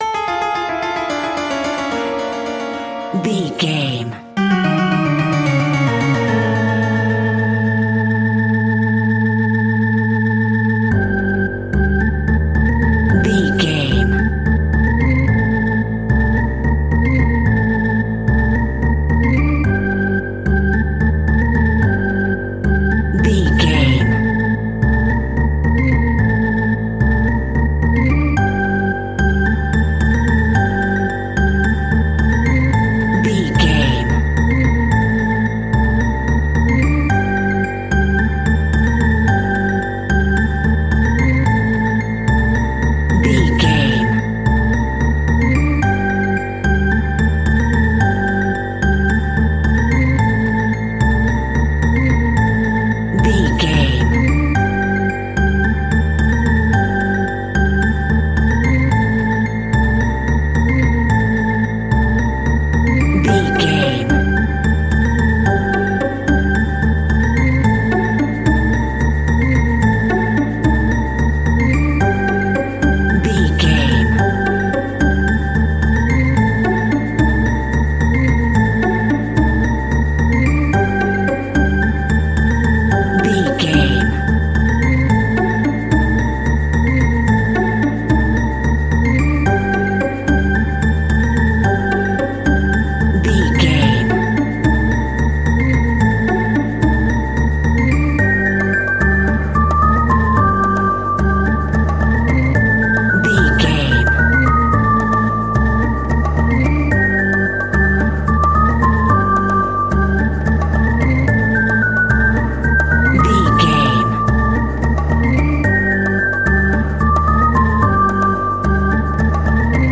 Aeolian/Minor
scary
ominous
dark
suspense
haunting
eerie
piano
electric organ
bass guitar
strings
percussion
electric piano
conga
mysterious
creepy
Horror Synths